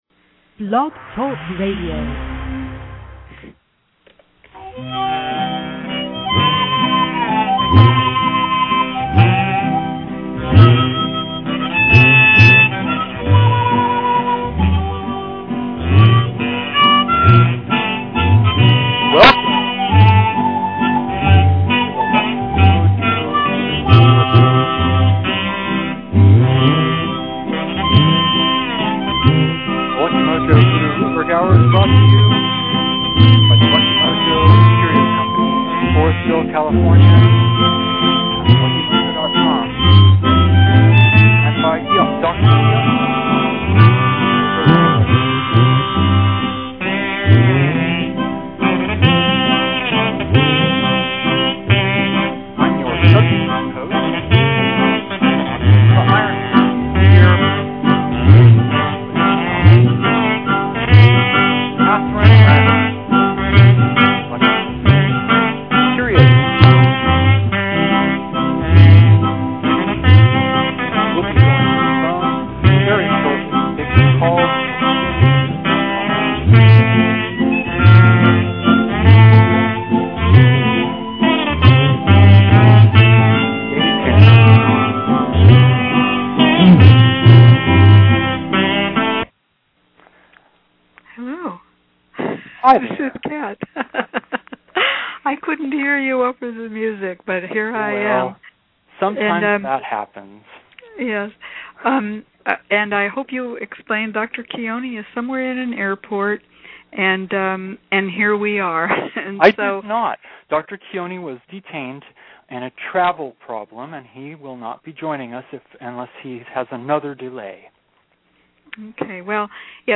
She calls to get advice on how best to deal with the troublesome new husband.